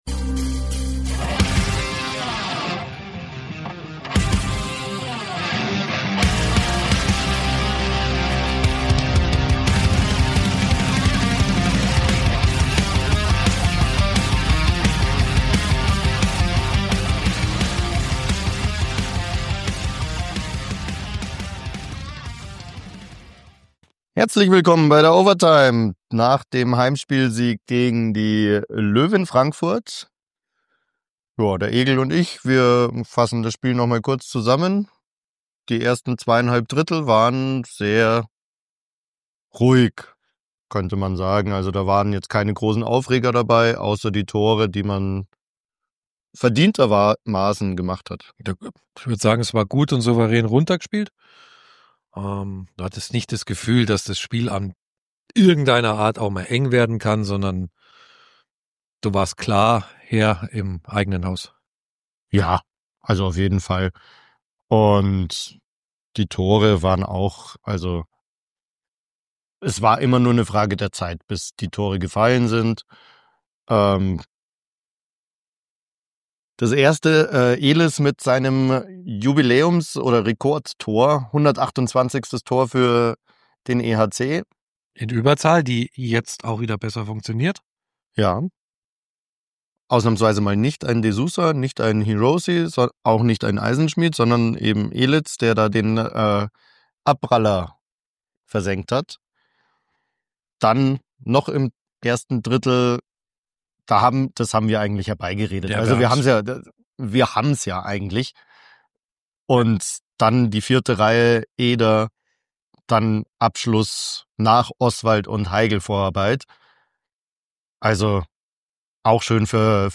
Weil während der Pressekonferenz die Verpflichtung des neuen Verteidigers gepostet wurde, gibt es nur die halbe Pressekonferenz.